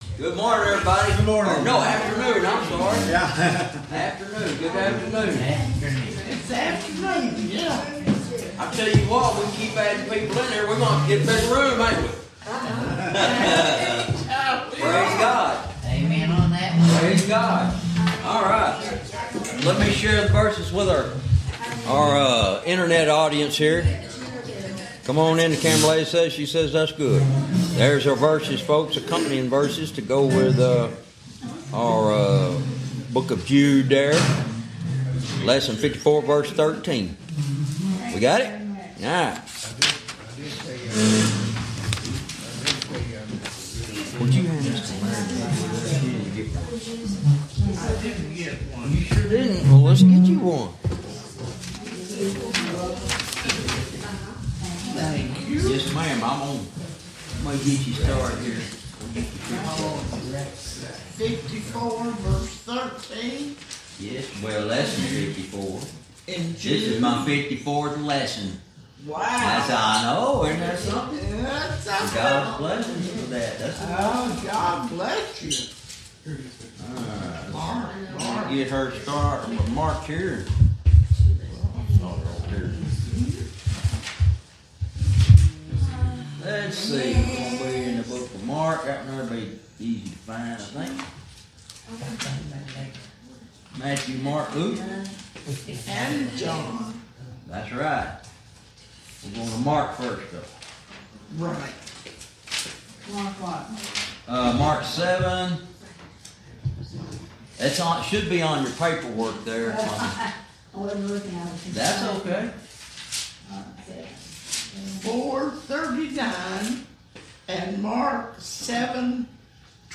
Verse by verse teaching - Lesson 54 verse 13 "White Caps of Shame"